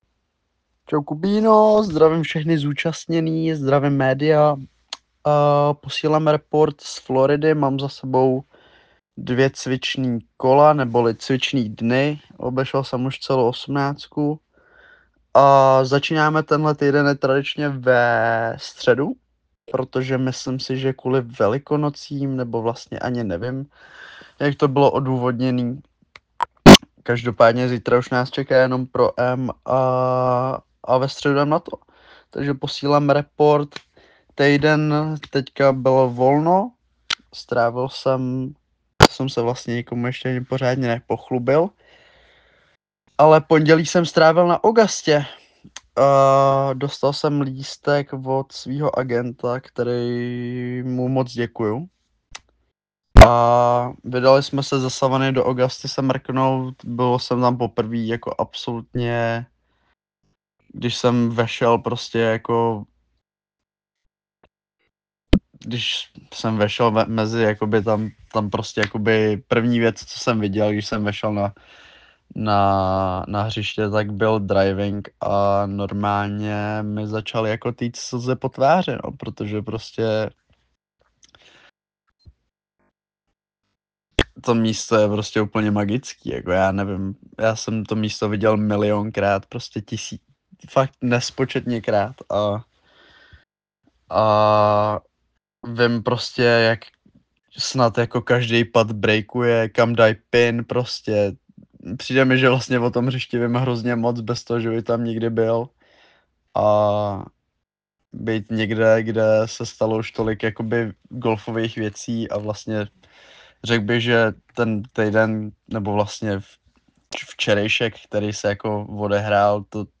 Hlasová zpráva